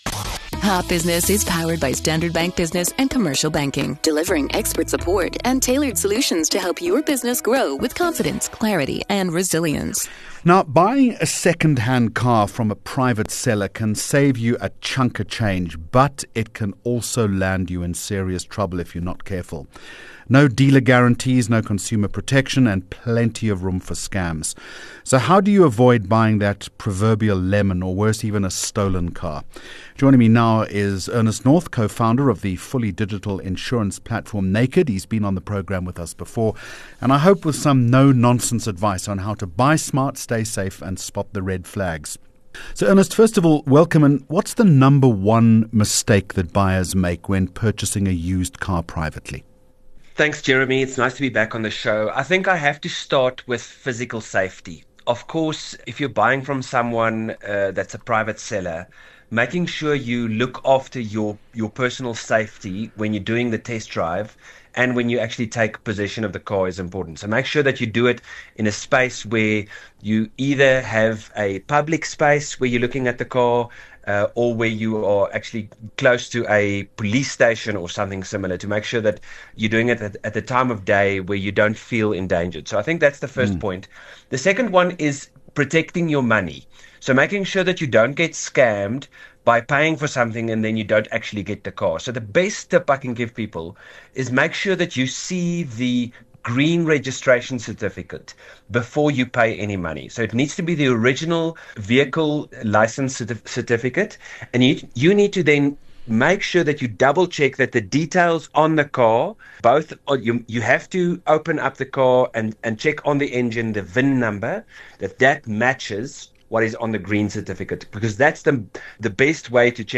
24 Jun Hot Business Interview